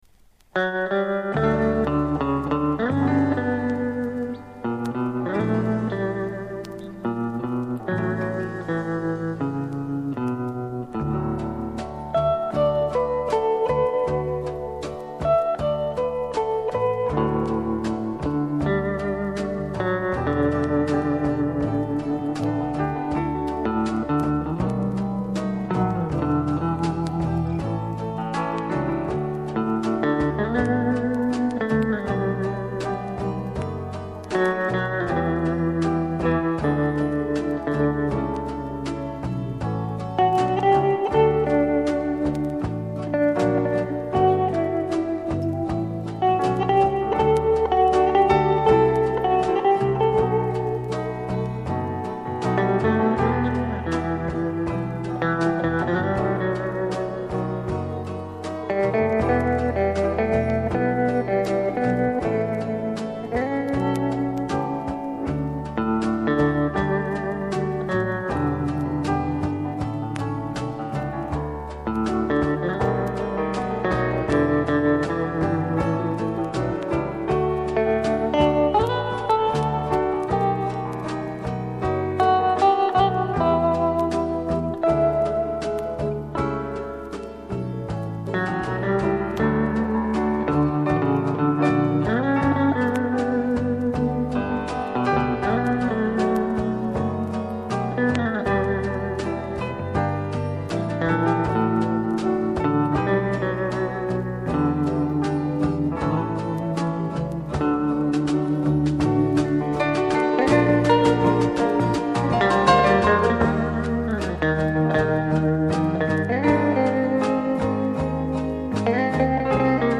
Известный гитарист.